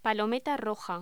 Locución: Palometa roja
voz